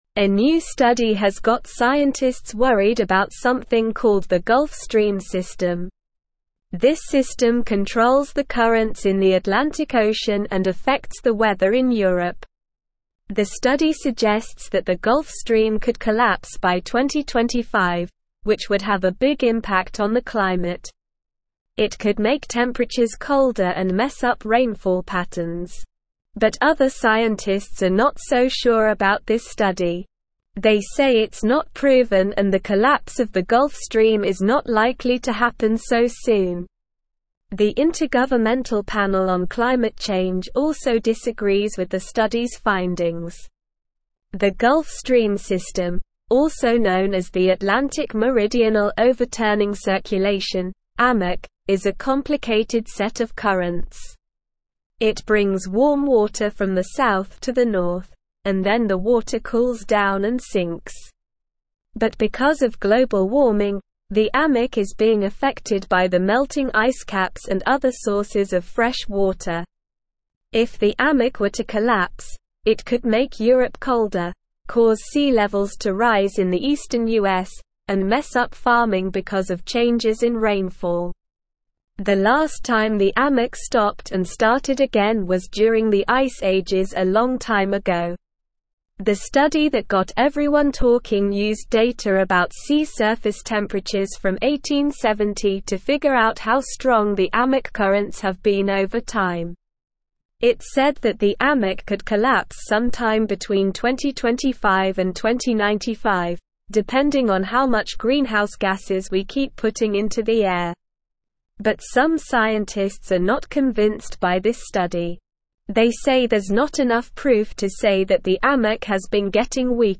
Slow
English-Newsroom-Upper-Intermediate-SLOW-Reading-Gulf-Stream-System-Could-Collapse-Scientists-Express-Reservations.mp3